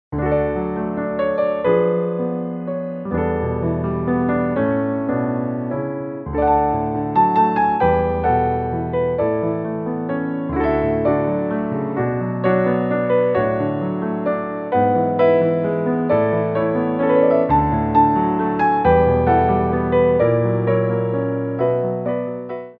3/4 (16x8)